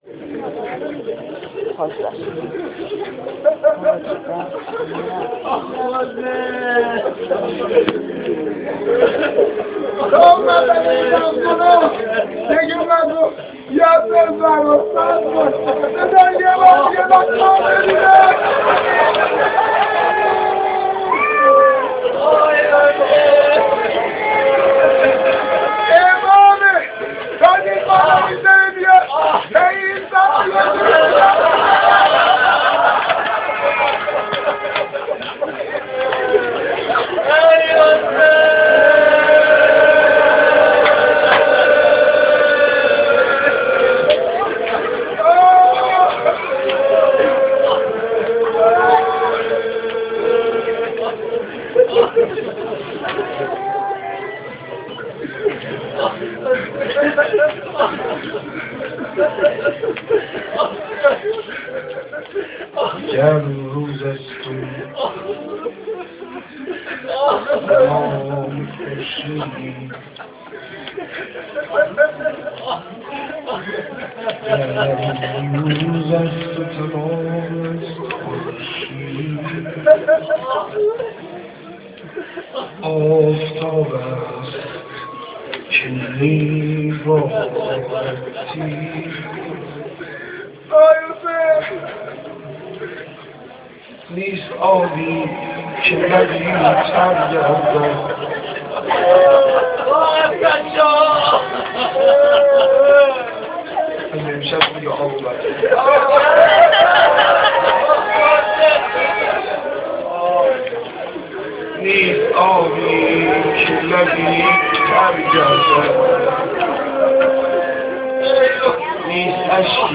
روضه خوانی7.amr
روضه-خوانی7.amr